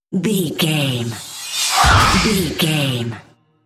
Airy whoosh hit horror squeal
Sound Effects
In-crescendo
Atonal
scary
ominous
eerie
woosh to hit